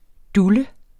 Udtale [ ˈdulə ]